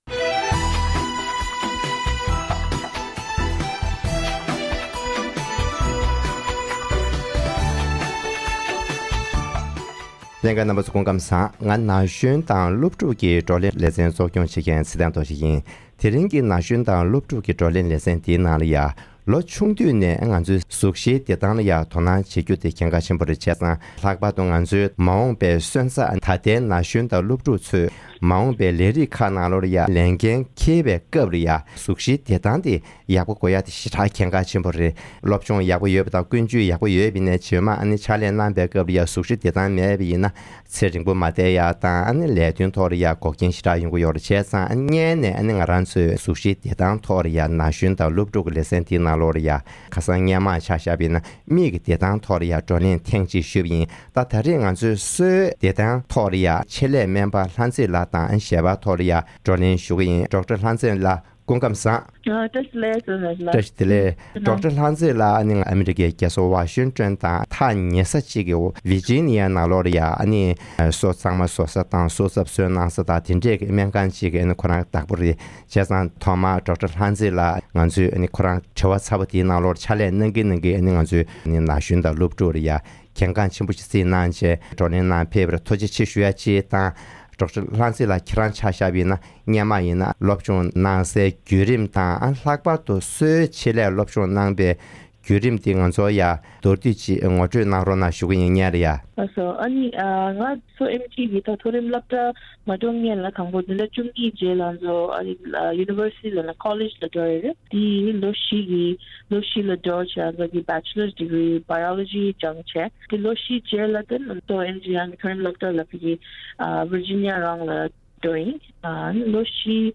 ན་གཞོན་དང་སློབ་ཕྲུག་གི་བགྲོ་གླེང་ལེ་ཚན
ཞལ་པར་བརྒྱུད་དེ་བགྲོ་གླེང་གནང་པ